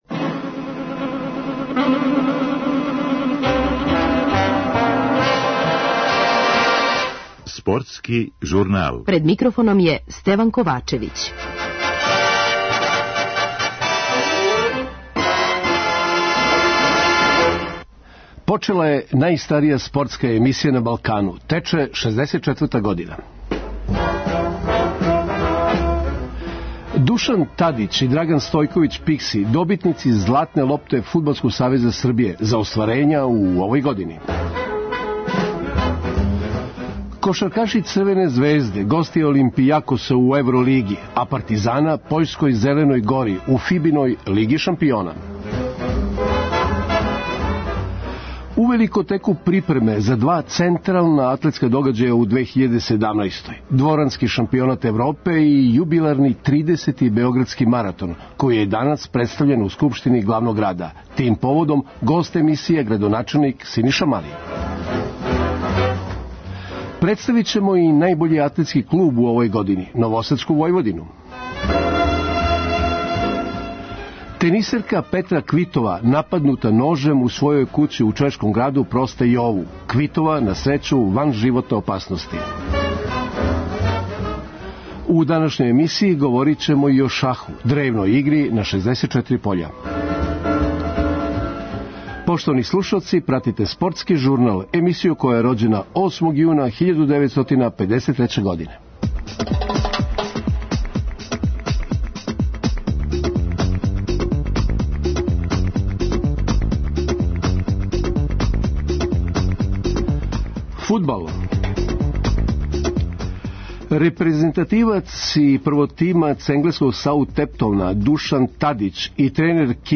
То је и био повод за разговор са градоначелником Синишом Малим.